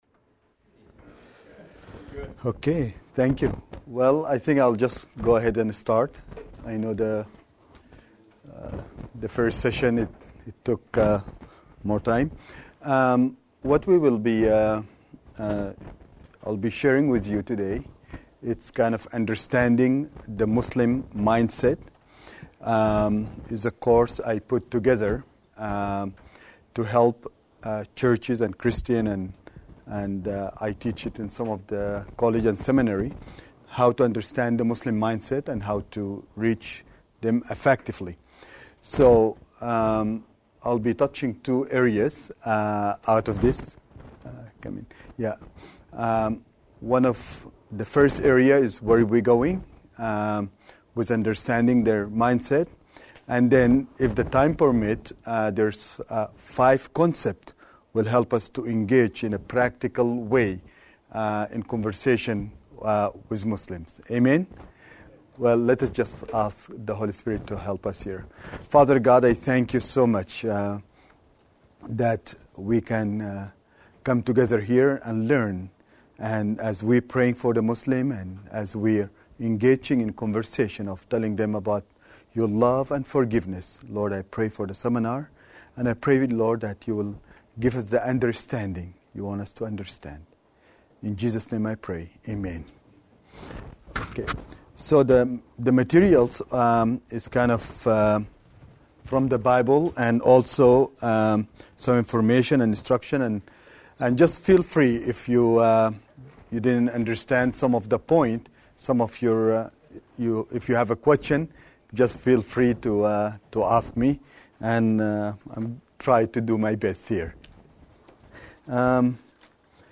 In this sermon, the speaker discusses his experience in sharing the word of God with Muslims.